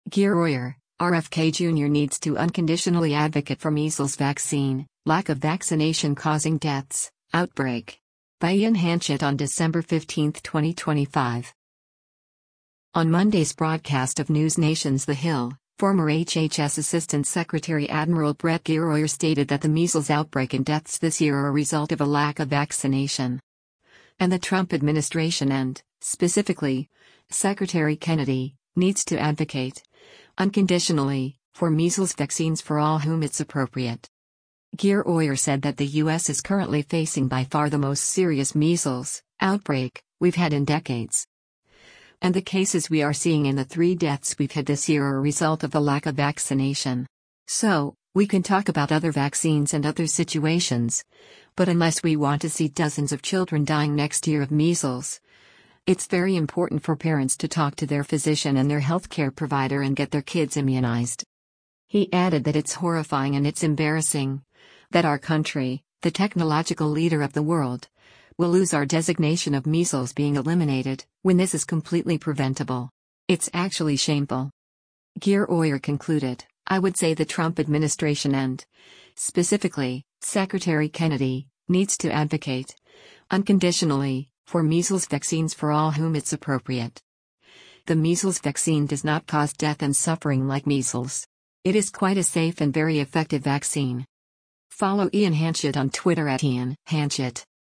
On Monday’s broadcast of NewsNation’s “The Hill,” former HHS Assistant Secretary Adm. Brett Giroir stated that the measles outbreak and deaths this year “are a result of a lack of vaccination.” And “the Trump administration and, specifically, Secretary Kennedy, needs to advocate, unconditionally, for measles vaccines for all whom it’s appropriate.”